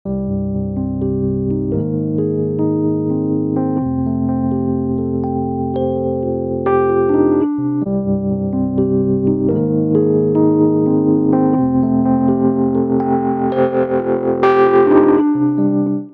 さりげないテープ・サチュレーション、霞んだリバーブ・テール、ビットクラッシュされたブレイクダウンまで、RC-20は常に“実験的な音作り”を誘います。